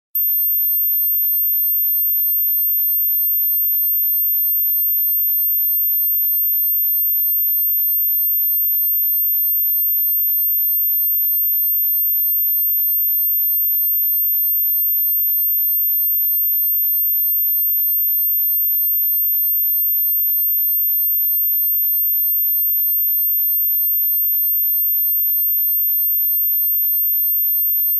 Category: dog sound effect